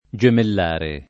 [ J emell # re ]